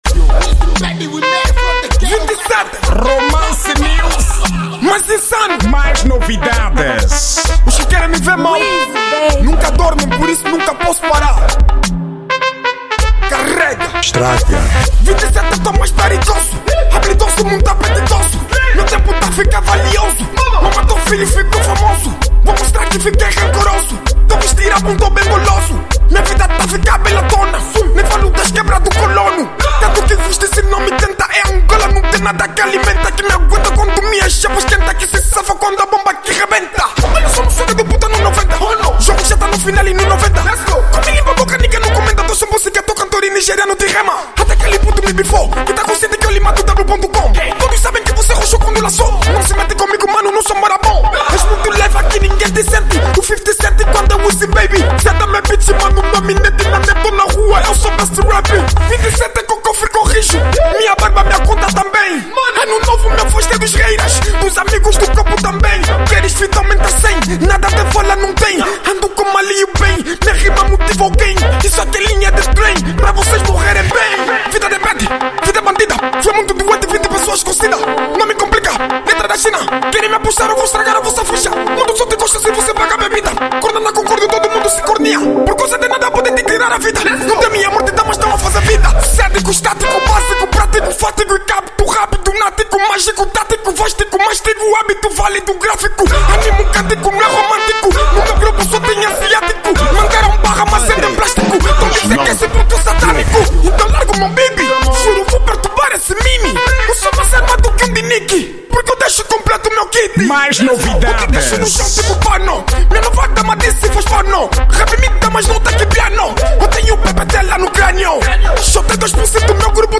Estilo: Rap Duro